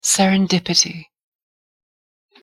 pronunciation_en_serendipity.mp3